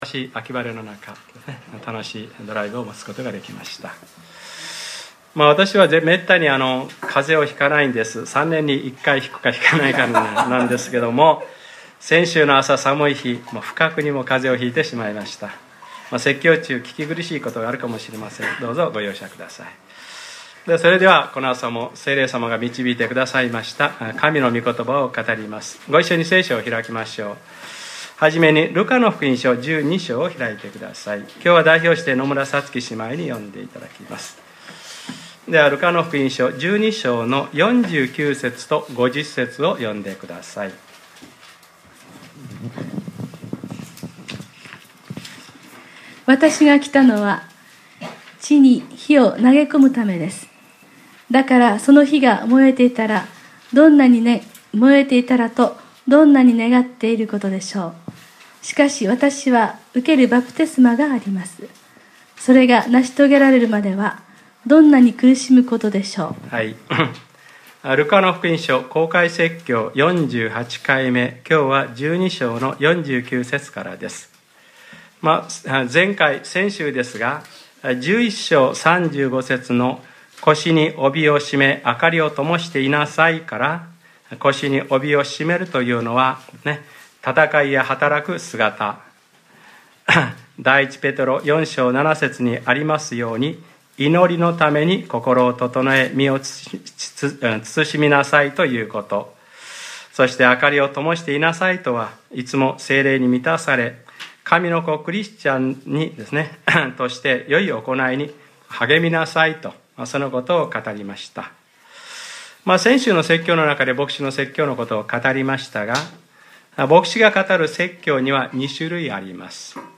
2014年11月09日）礼拝説教 『ルカｰ４８：聖霊のバプテスマ』